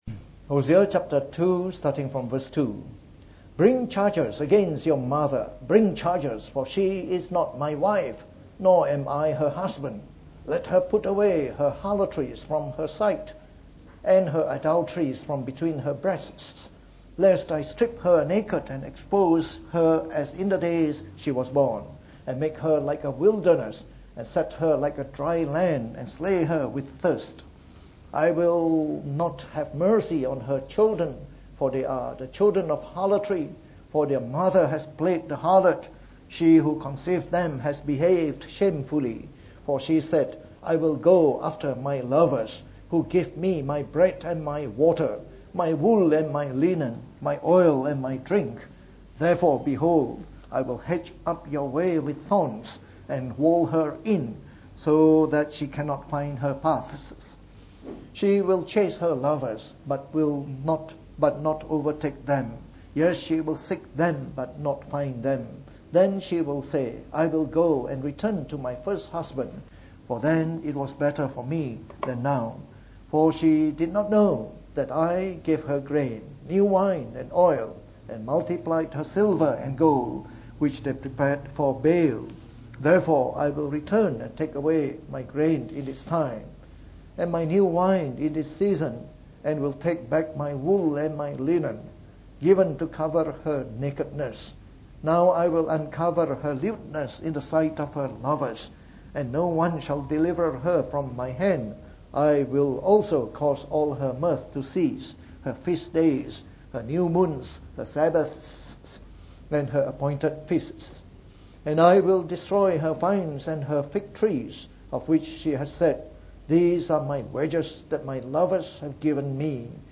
From our series on the Book of Hosea delivered in the Morning Service.